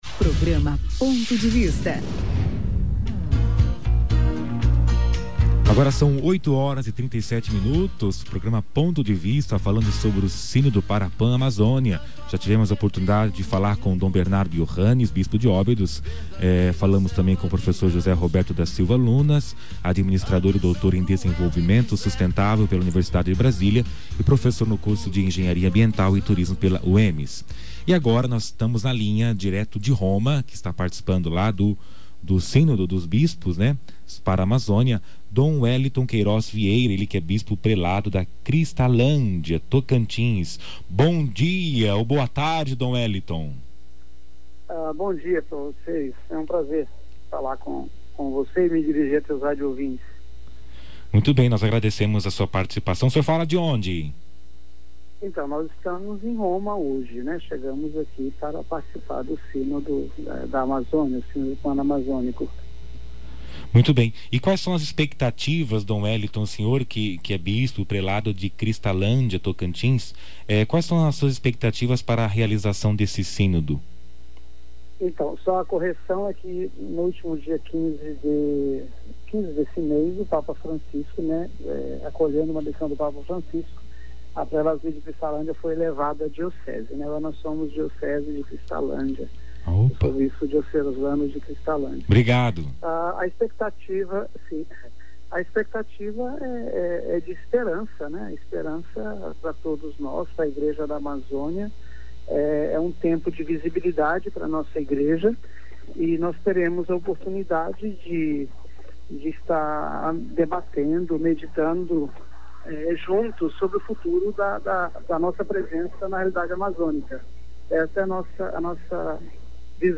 Dom Bernardo Johannes BAHLMANN, Bispo de Óbidos/ PA
Dom Wellinton Queiroz Vieira - Bispo Prelado de Cristalândia / Tocantins – TO está no Vaticano / Roma